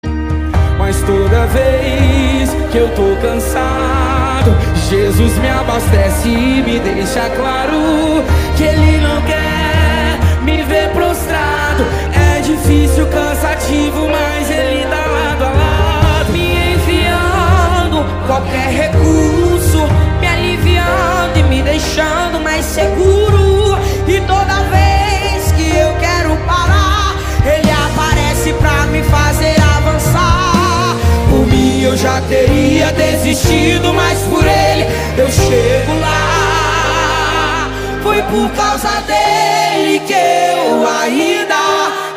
Categoria POP